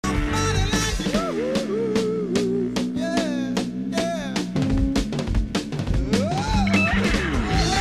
自分が一番好きな箇所は後半の方での「タッタッタッタッ〜タッドロロンタッドロロン〜・・・」のところです。